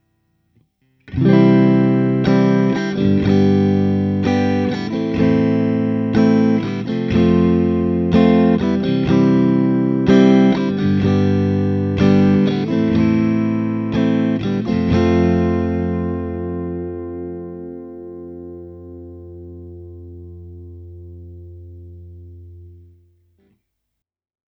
1. C - G - Am - F  (I - V -vi - IV)
1.-C-G-Am-F.wav